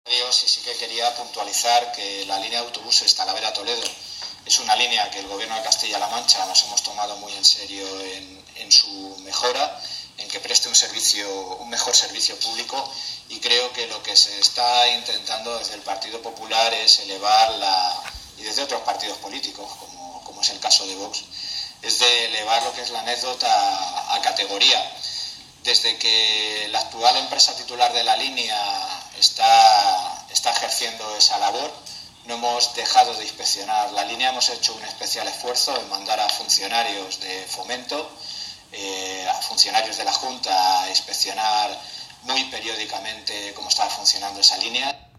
David Gómez responde a las preguntas de los periodistas sobre el servicio de autobuses Talavera-Toledo